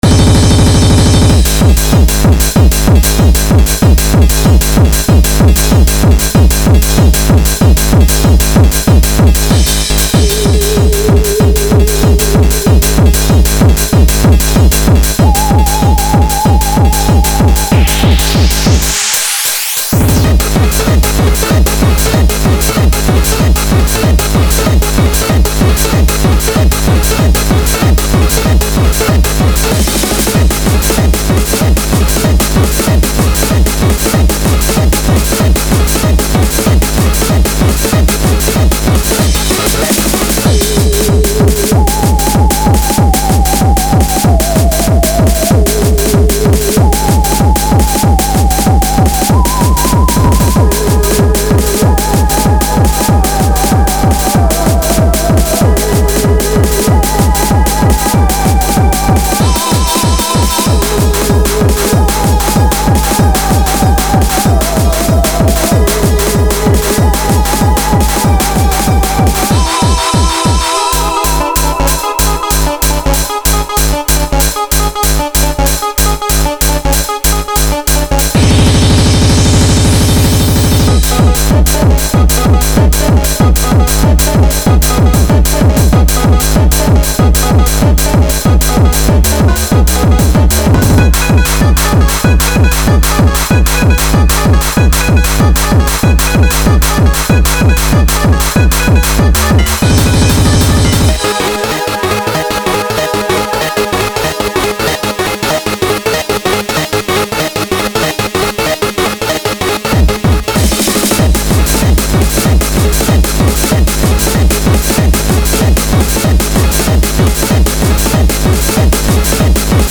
2020 play_arrow It's kind of a hard trance/hardcore thing, but with some seriously dark chords. My fear was based on alien invasions.